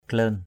/klə:n/ 1.